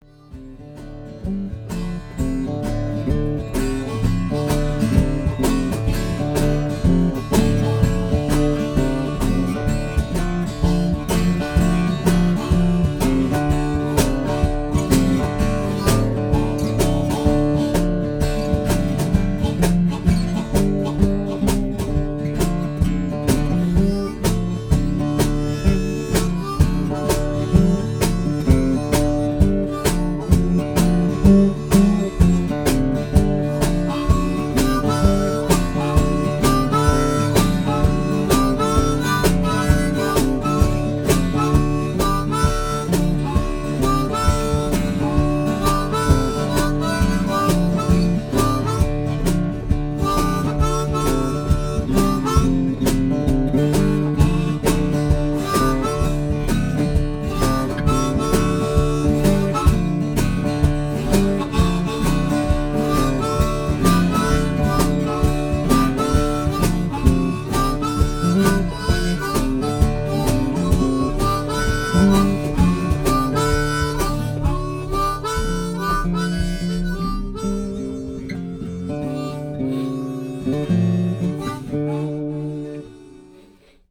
Länge: 1:23 / Tempo: 65 bpm / Datum: 11.04.2016